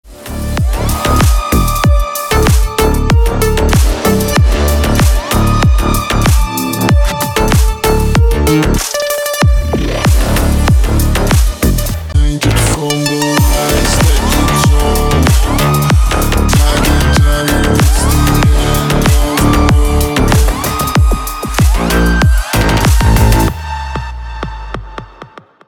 Громкие звонки, звучные рингтоны
громкие рингтоны 2024